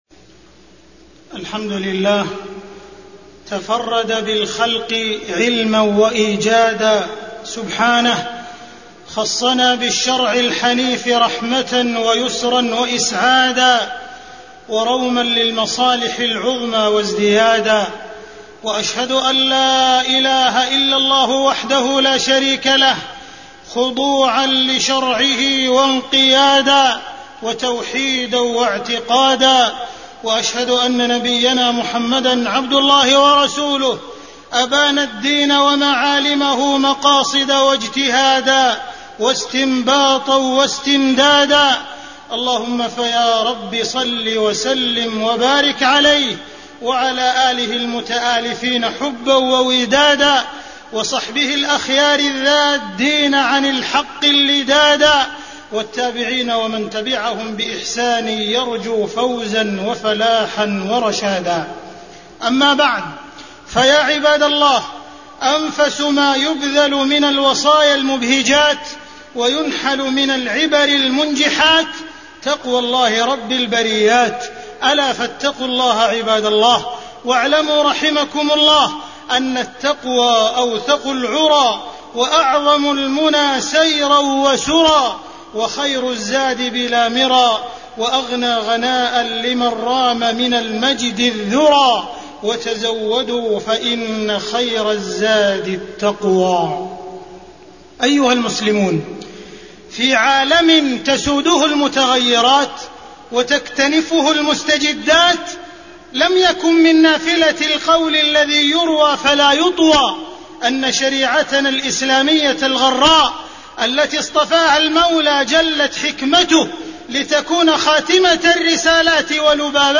تاريخ النشر ٢٦ ربيع الثاني ١٤٢٩ هـ المكان: المسجد الحرام الشيخ: معالي الشيخ أ.د. عبدالرحمن بن عبدالعزيز السديس معالي الشيخ أ.د. عبدالرحمن بن عبدالعزيز السديس ضوابط الاجتهاد